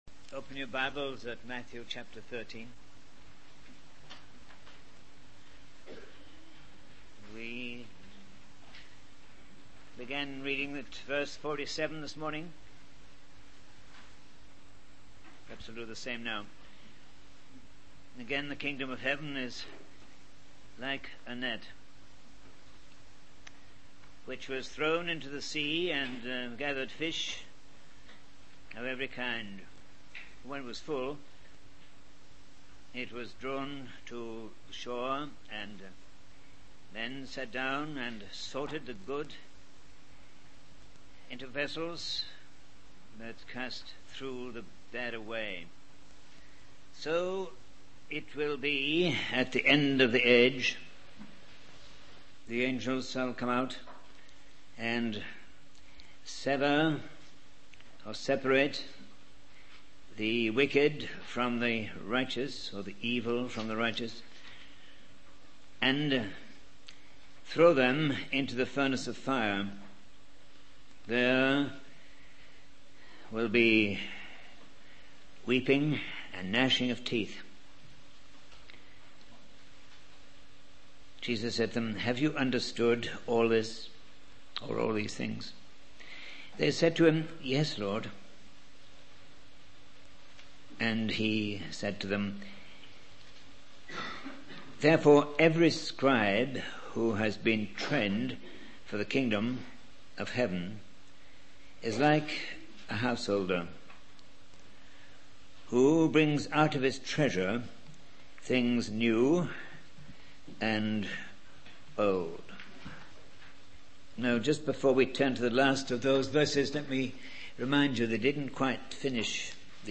In this sermon, the speaker begins by discussing the power and control that a householder in scripture had, comparing it to the role of a householder in the queen's household. The speaker then uses the analogy of the moon reflecting the brightness of the sun to describe how Christians are meant to reflect Jesus and his glory. The speaker emphasizes the importance of Christians reflecting Christ rather than relying on programs or committees.